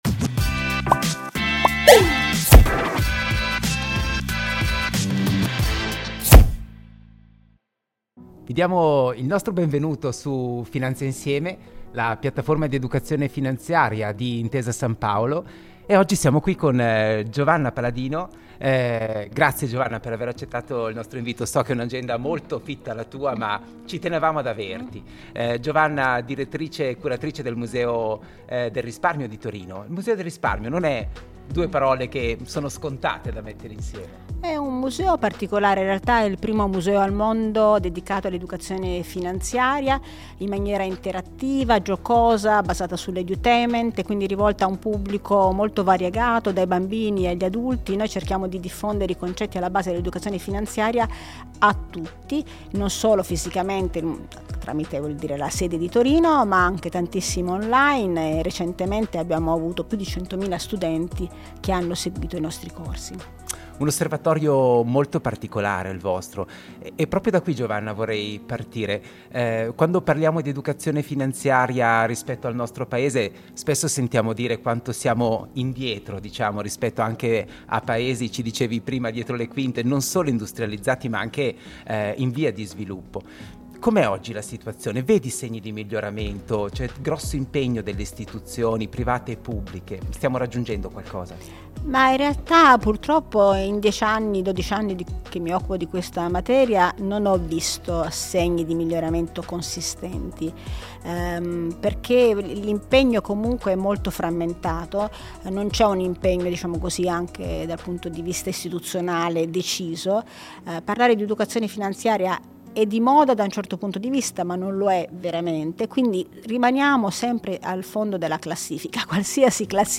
Un modo coinvolgente per parlare di consapevolezza finanziaria, attraverso conversazioni informali ma ricche di contenuti, con personalità esperte e voci autorevoli che aiutano ad approfondire temi di attualità e di interesse.